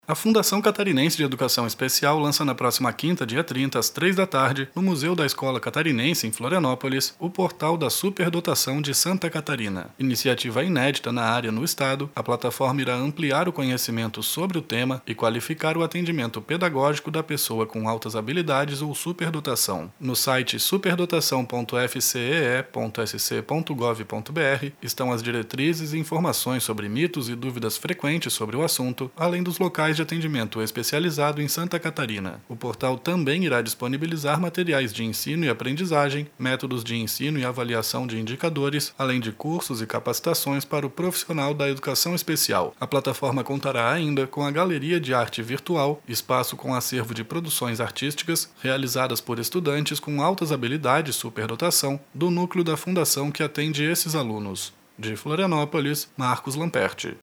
3. Radio Secom